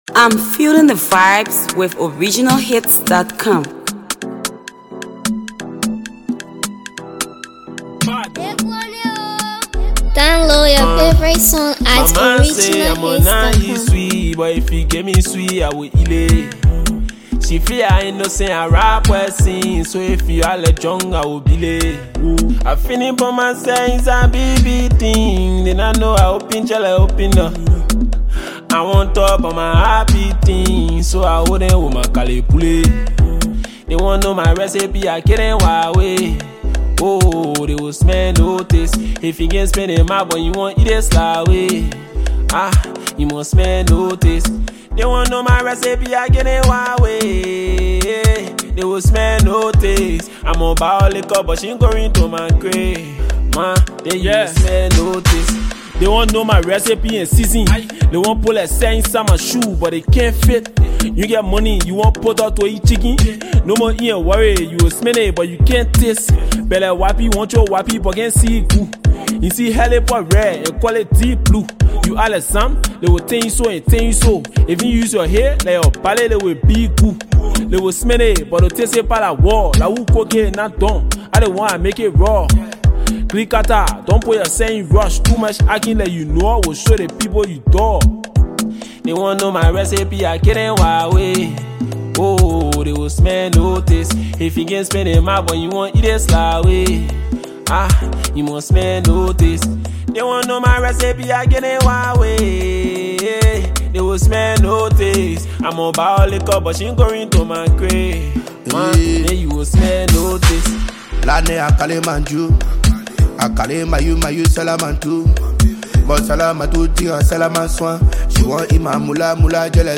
Trap/Drill
Afropop
studio release
blending sharp lyricism with captivating rhythms